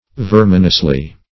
verminously - definition of verminously - synonyms, pronunciation, spelling from Free Dictionary Search Result for " verminously" : The Collaborative International Dictionary of English v.0.48: Verminously \Ver"min*ous*ly\, adv.